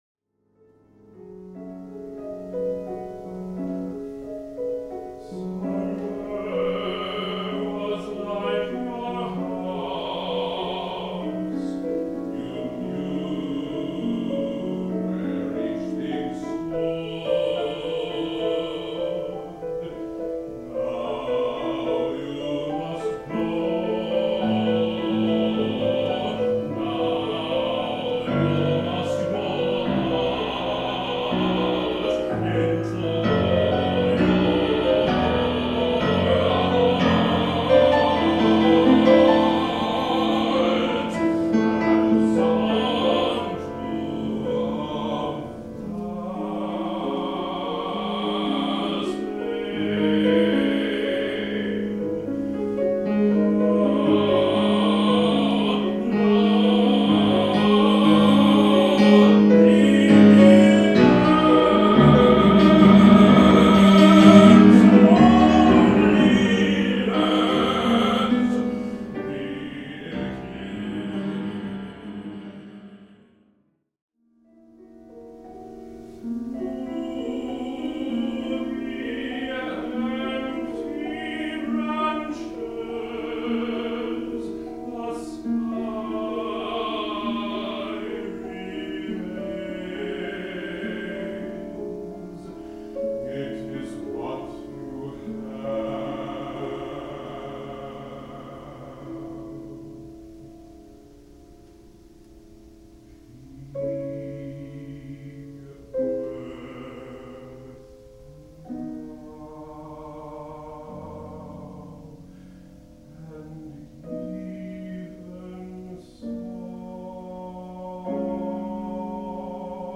Scored for: mezzo-soprano, baritone and piano.